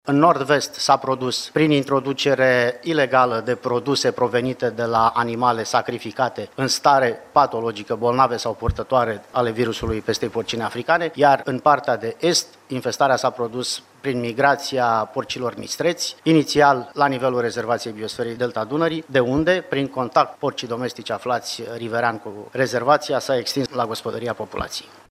Proprietarii animalelor sacrificate din cauza pestei porcine africane vor primi despăgubiri între 8 şi 12 lei pe kilogram, spune preşedintele ANSVSA, Geronimo Brănescu.